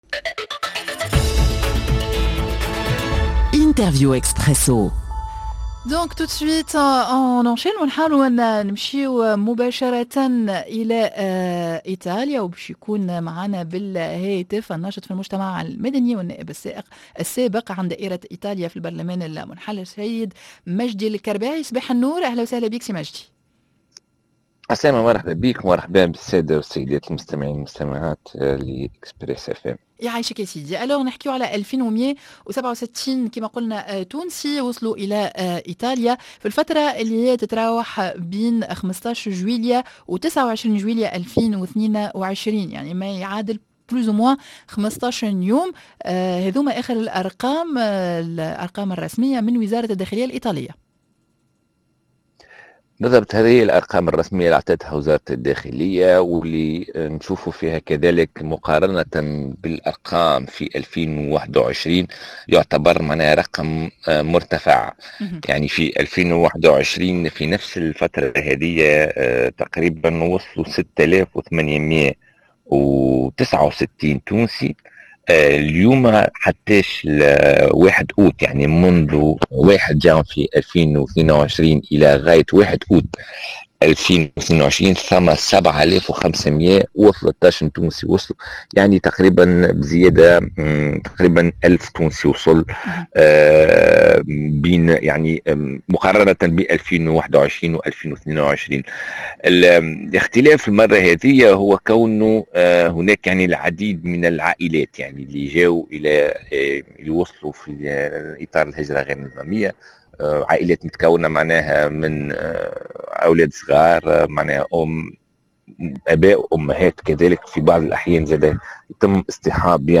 2167 تونسي وصلوا لإيطاليا حسب أرقام وزارة الداخلية الإيطالية ، في ظرف 15 يوم من 15 جويلية 2022 الى 29 جويلية 2022 ضيفنا الناشط في المجتمع المدني والنائب السابق عن دائرة إيطاليا في البرلمان المنحل مجدي الكرباعي